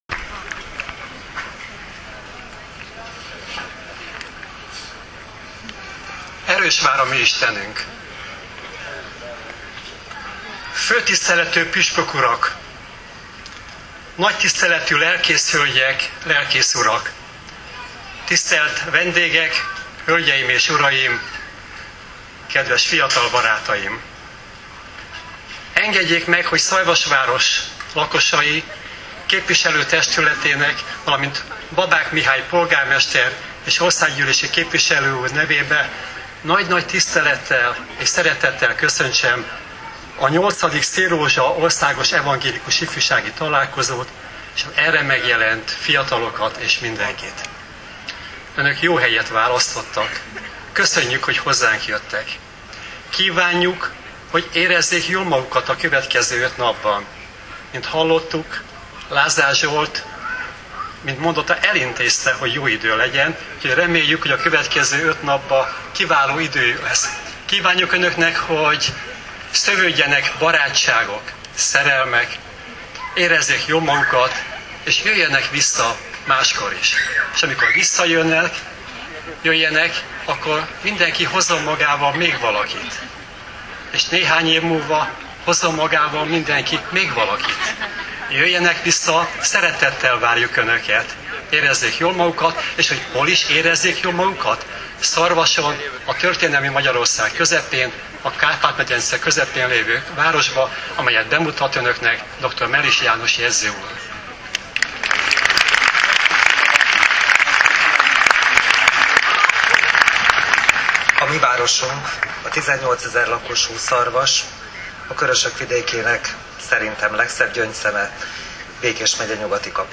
2010. július 14-én a Szélrózsa – 8. Országos Evangélikus Ifjúsági Találkozón a megnyitó ünnepségen több köszöntőt is hallhattunk.
Alpolgármesteri és jegyzői köszöntők   2.2 MB
alpolgarmesteri-jegyzoi-koeszoentok_07.14.wma